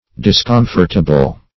discomfortable - definition of discomfortable - synonyms, pronunciation, spelling from Free Dictionary
Discomfortable \Dis*com"fort*a*ble\, a. [Cf. OF.